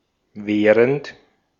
Ääntäminen
US : IPA : [ɪn]